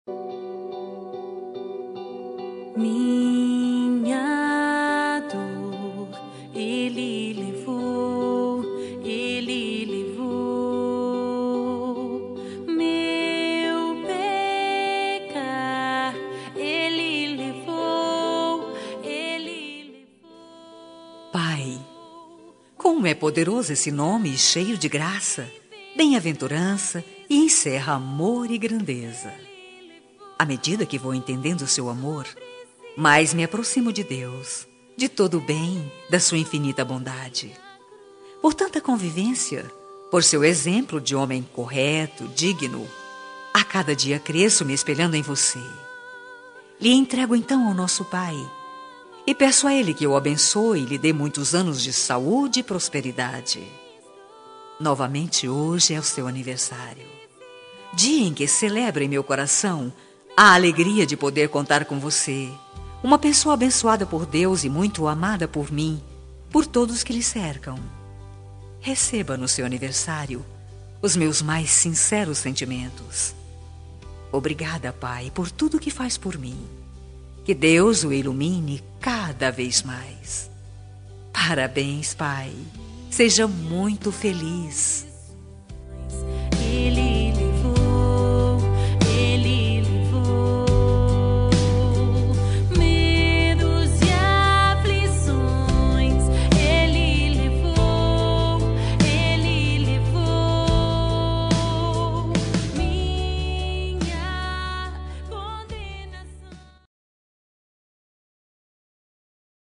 Aniversário de Pai Gospel – Voz Feminina – Cód: 6078